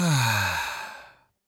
Gentle Sigh
A soft, relieved sigh expressing contentment or gentle exhaustion
gentle-sigh.mp3